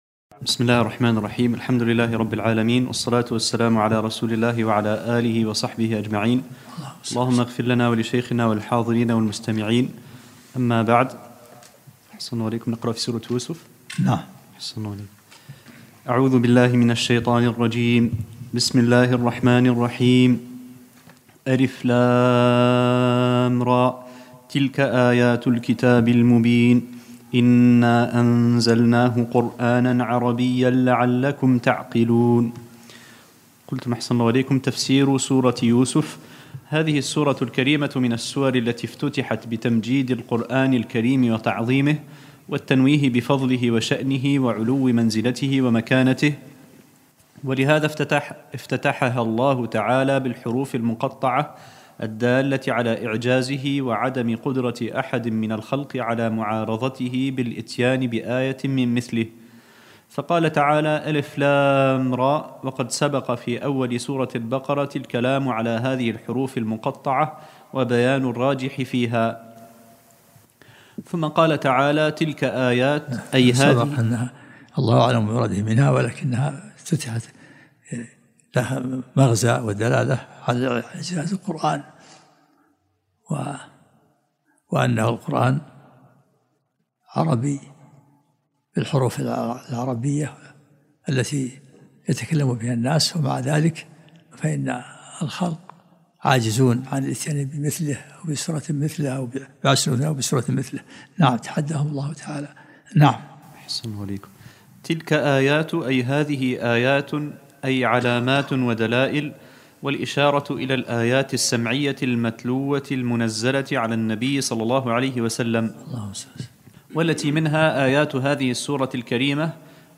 الدرس الأول من سورة يوسف